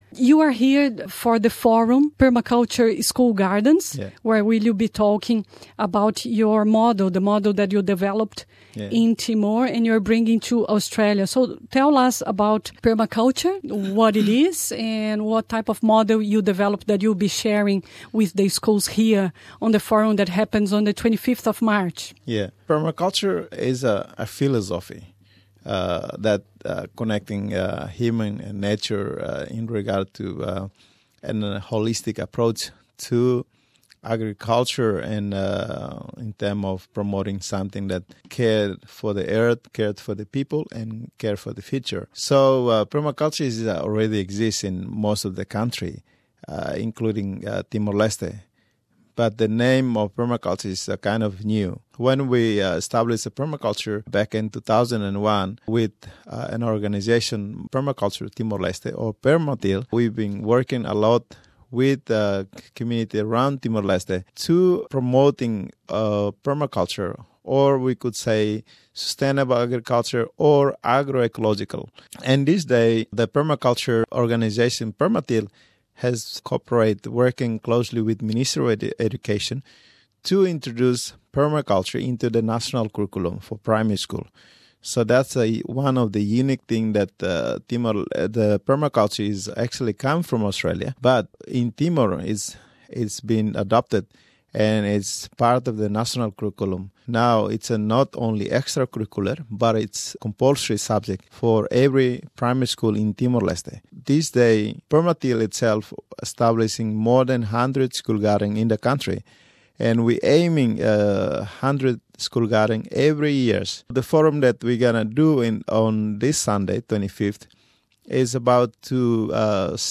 Nessa entrevista ele fala sobre os programas de permacultura que desenvolveu nas escolas em Timor Leste, baseado em um modelo australiano. Mais de cem escolas timorenses já adotaram o programa que faz parte do currículo de todas as escolas primária em timor.